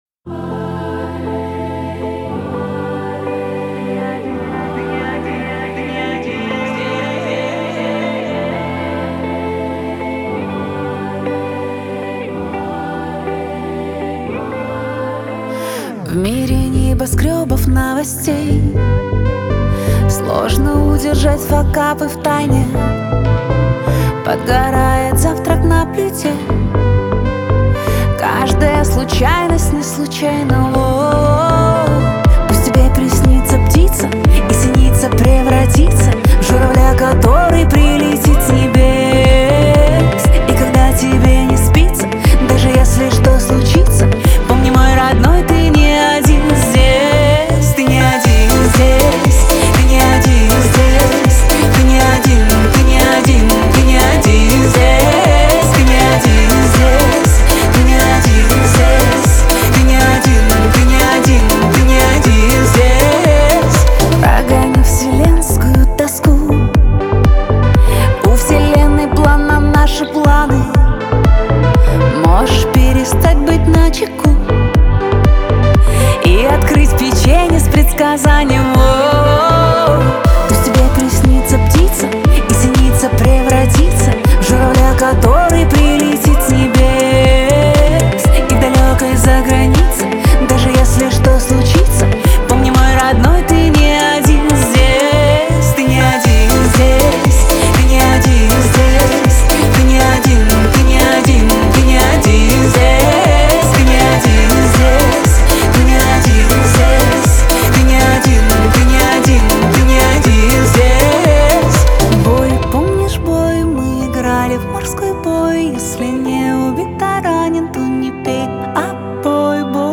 Жанр — поп, настроение — оптимистичное и ободряющее.